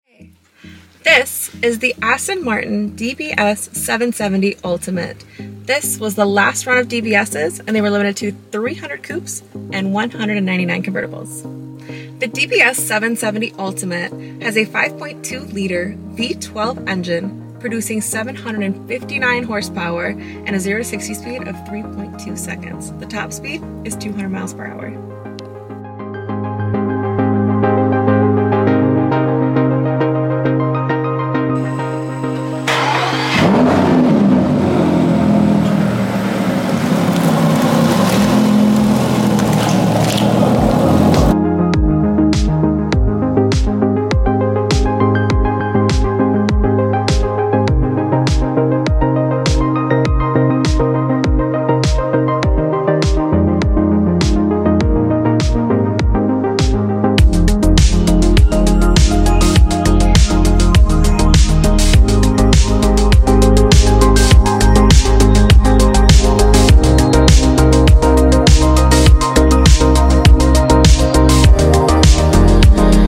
This Aston Martin DBS 770 sound effects free download
Engine: 5.2-liter V-12